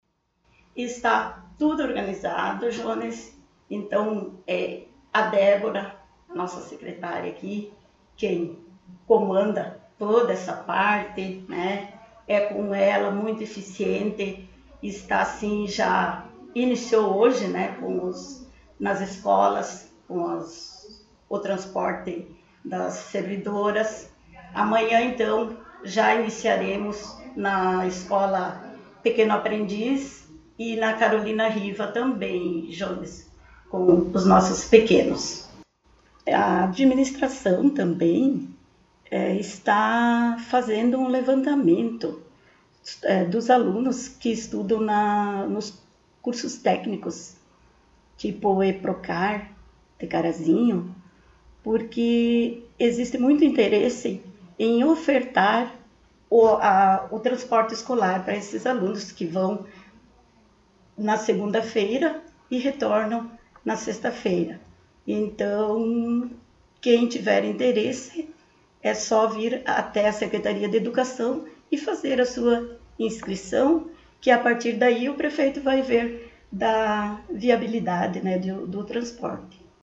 Secretária Municipal de Educação, Cultura e Desporto concedeu entrevista